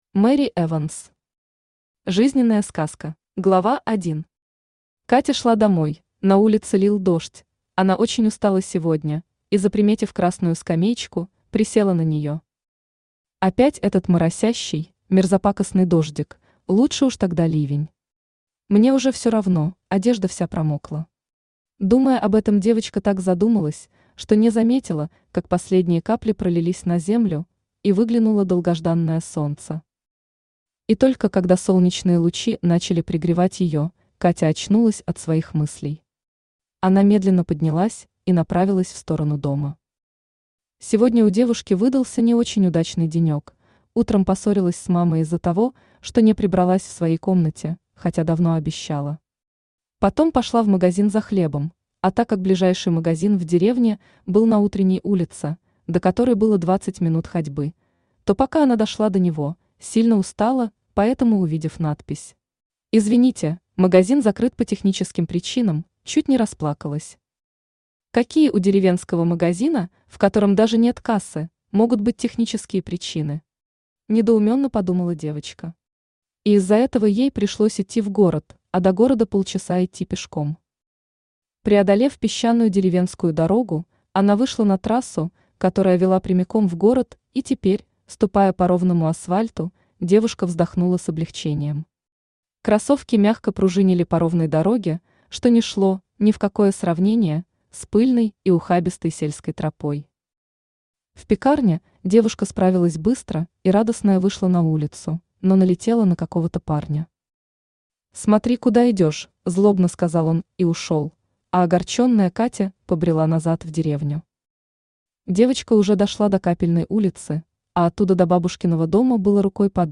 Аудиокнига Жизненная сказка | Библиотека аудиокниг
Aудиокнига Жизненная сказка Автор Мэри Эванс Читает аудиокнигу Авточтец ЛитРес.